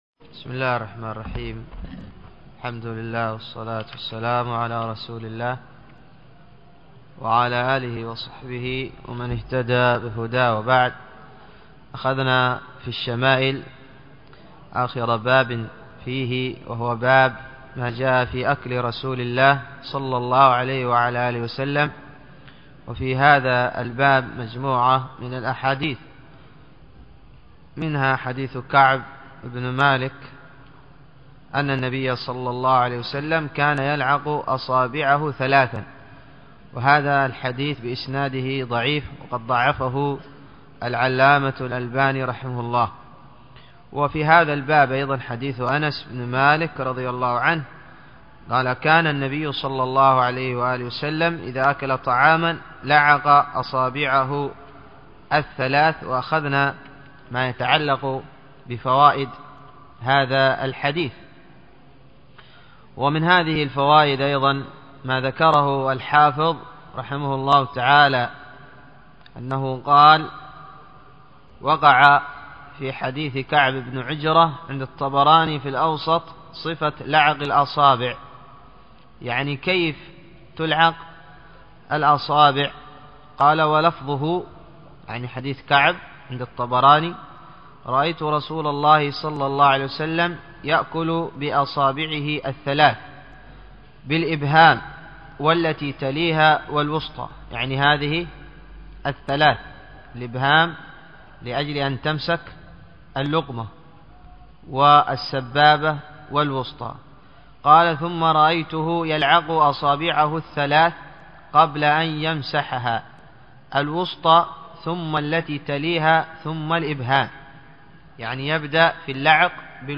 الدرس الحادي و الثلاثون